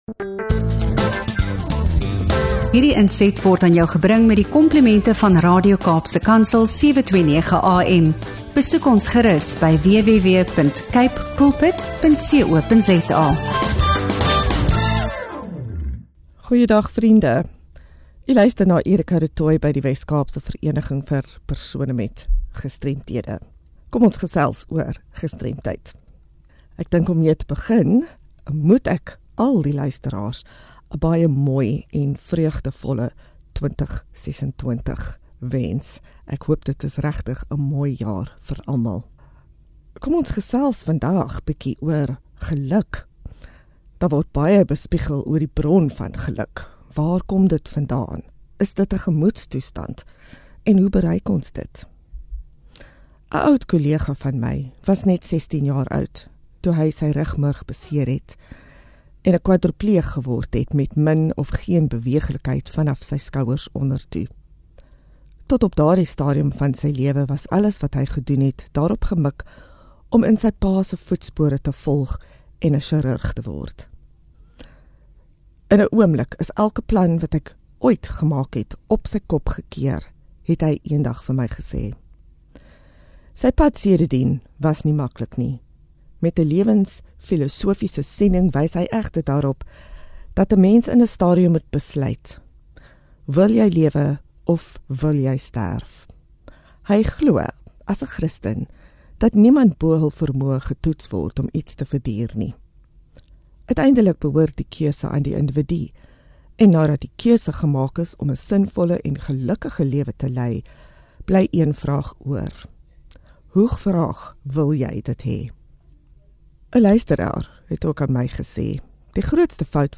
6 Jan GEMEENSKAP GESTREMDHEID - Geluk, Gestremdheid en Lewenskeuses: ’n Diep Gesprek oor ’n Sinvolle en Betekenisvolle Lewe